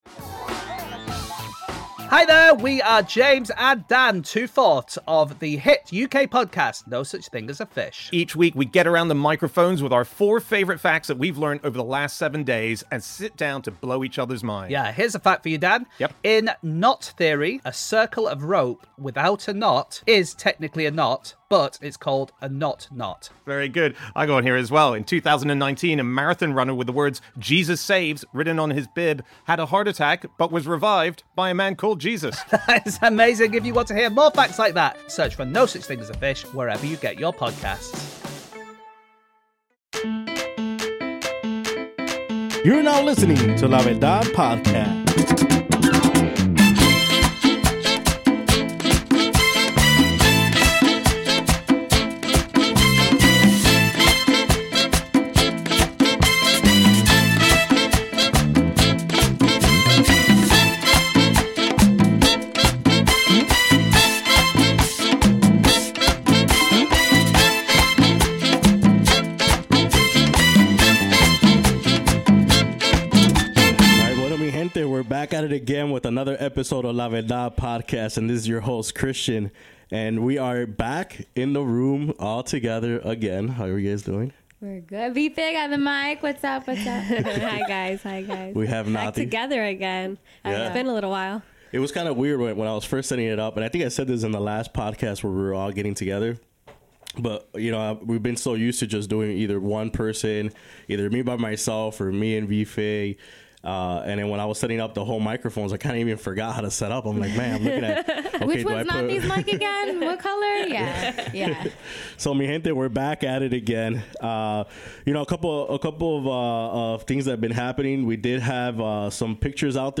Our conversation covers a variety of topics that include: